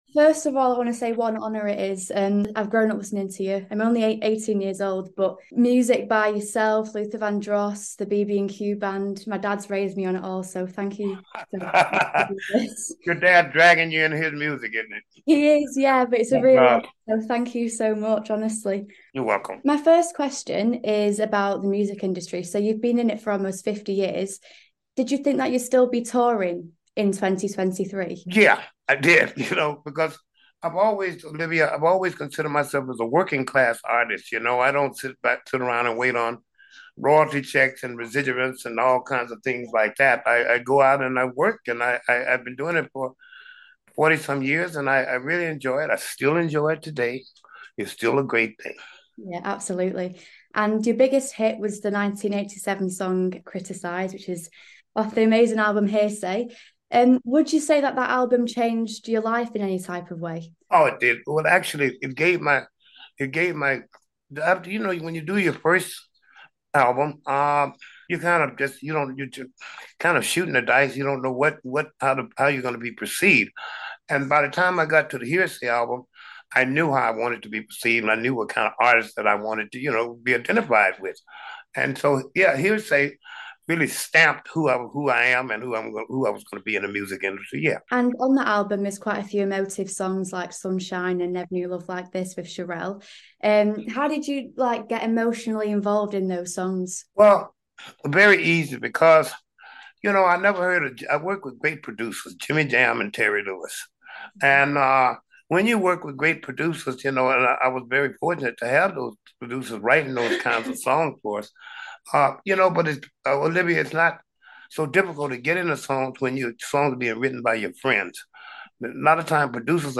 INTERVIEW: Alexander O'Neal on his iconic career and last tour